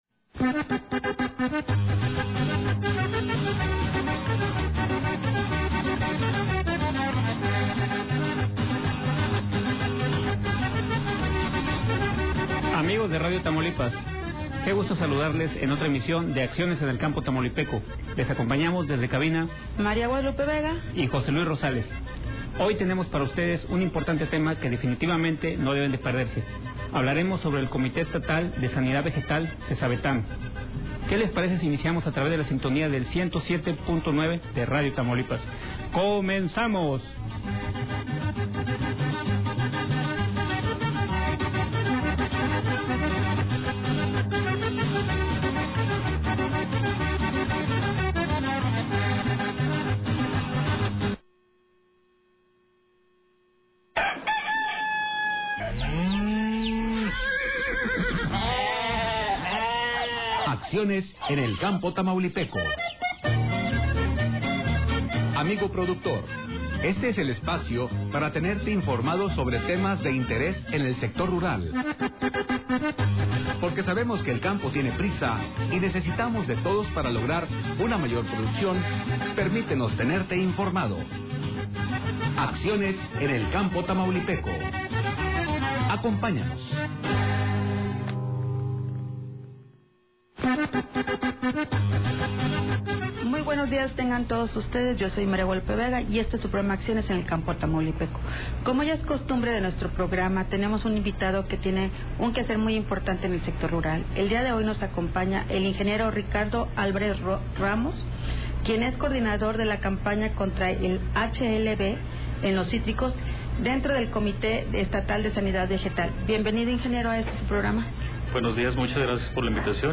Comentó la afectación que se tiene en las abejas, las medidas de control que se están aplicando juntos con los apicultores para tener un mejor control biológico. Finalizó la entrevista explicando sobre los controles químicos que se están efectuando para tener un mejor control de las plagas.